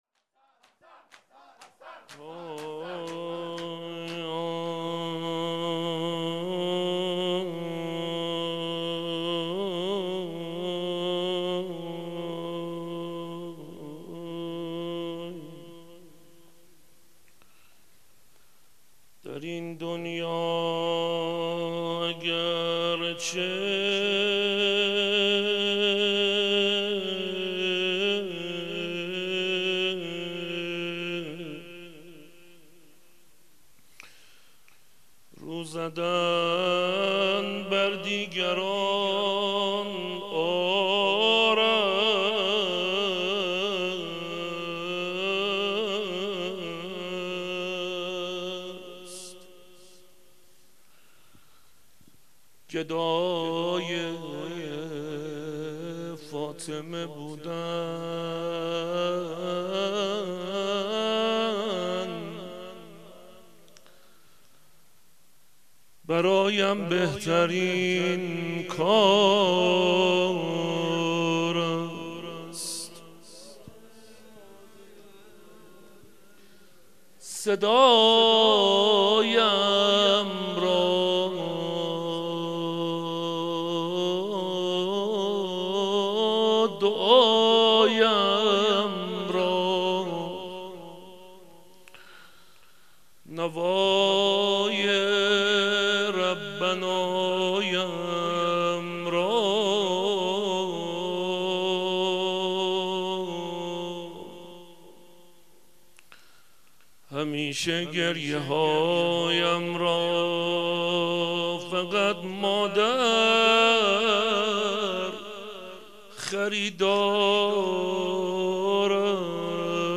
شب پنجم دهه فاطمیه
روضه حضرت زهرا سلام الله علیها